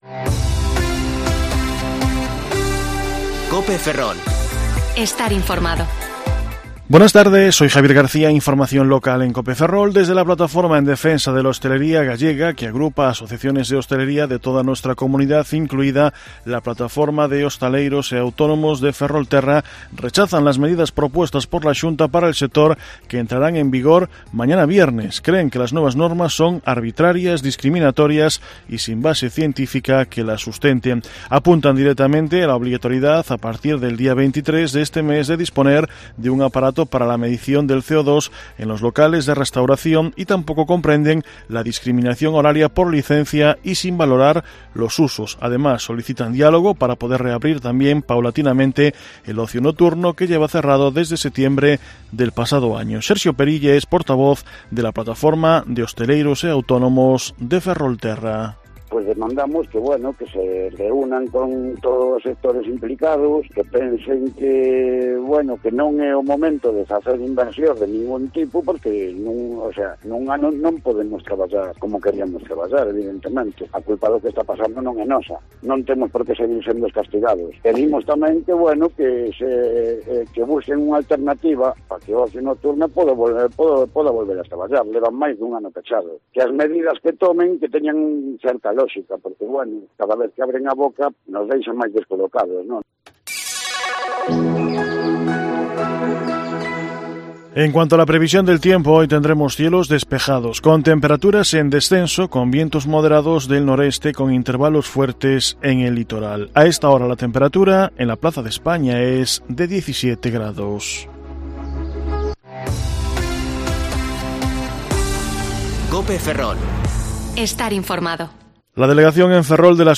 Informativo Mediodía COPE Ferrol 15/4/2021 (De 14,20 a 14,30 horas)